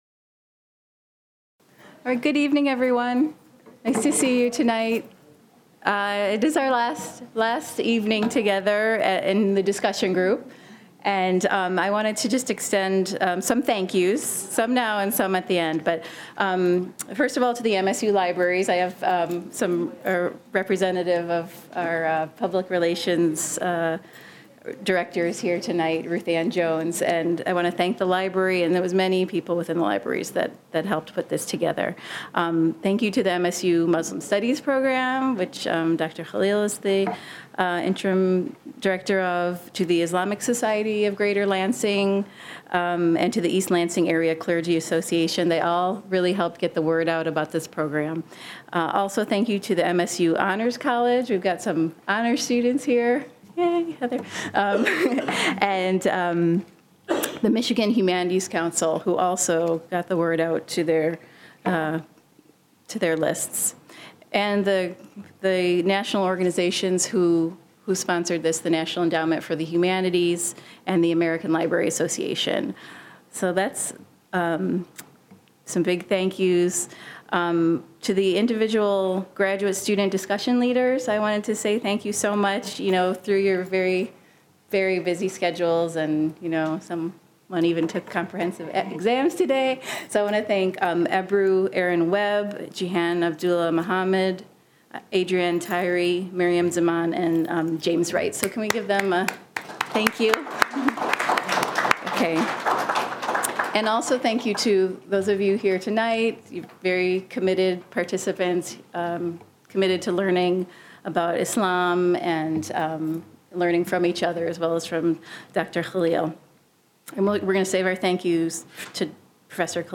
The small group discussions are not heard.
Held at the MSU Main Library.